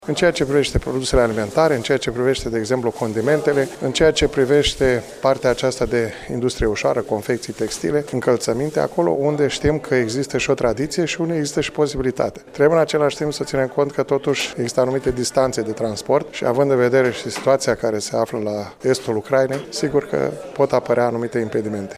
Astăzi, la Iaşi, s-a desfăşurat primul Forum Economic al Oamenilor de afaceri armeni din România şi Republica Moldova.